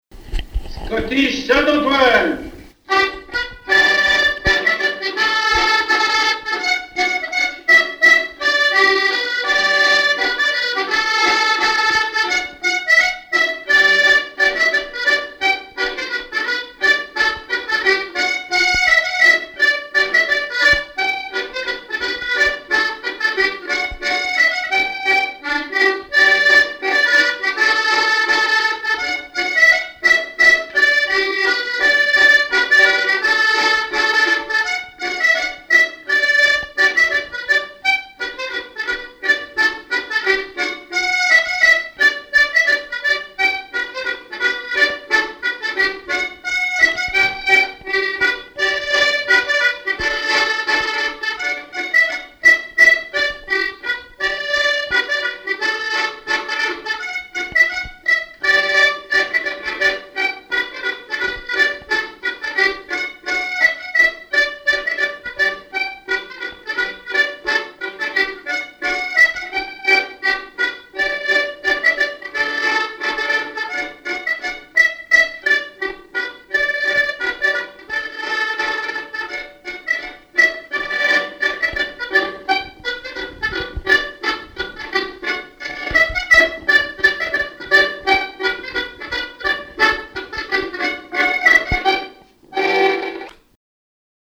Chants brefs - A danser Résumé : Saint-Antoine avec son violon, fait danser le filles, fait danser les filles.
danse : scottich trois pas
Pièce musicale inédite